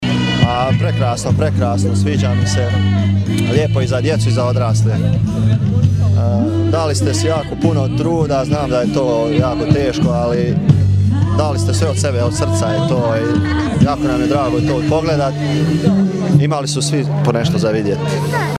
Prikaz su gledale i mlade obitelji iz Gline: